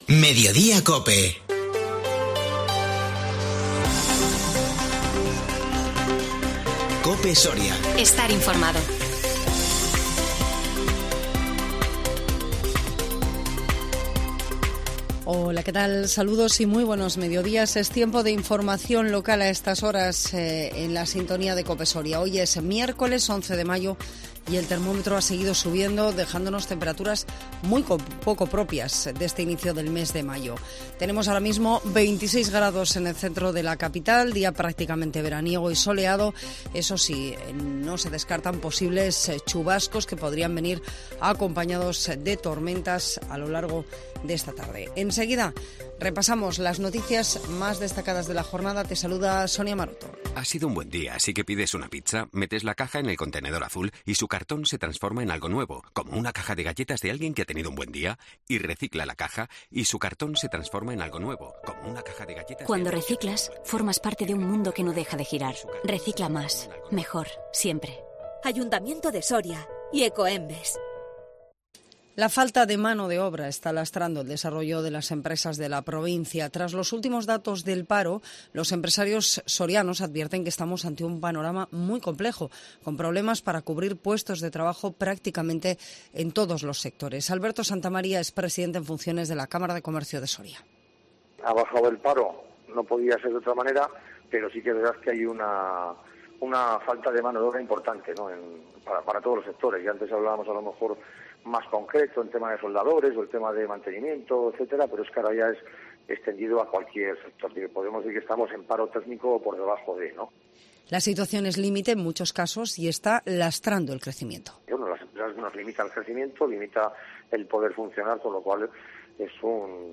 INFORMATIVO MEDIODÍA COPE SORIA 11 MAYO 2022